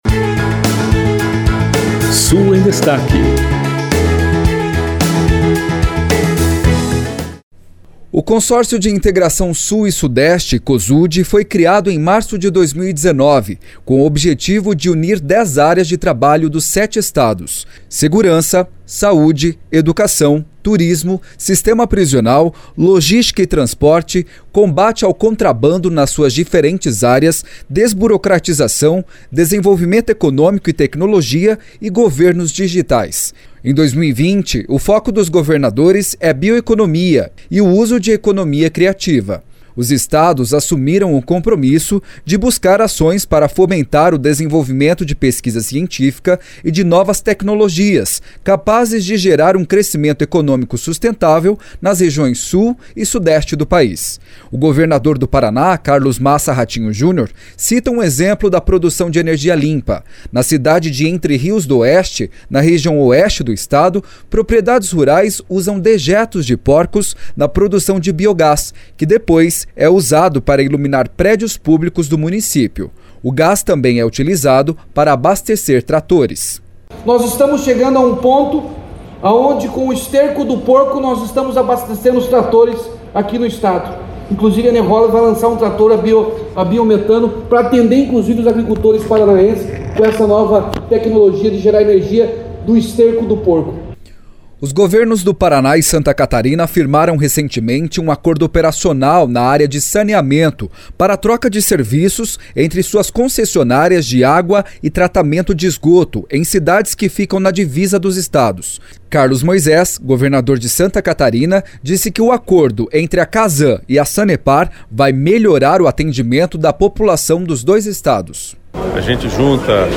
O governador do Paraná, Carlos Massa Ratinho Junior, cita um exemplo da produção de energia limpa.
Carlos Moisés, governador de Santa Catarina, disse que o acordo entre a Casan e a Sanepar vai melhorar o atendimento da população dos dois estados.
O governador do Rio Grande do Sul, Eduardo Leite, afirma que o trabalho das gestões estaduais precisa promover o desenvolvimento econômico, mas respeitando o meio-ambiente.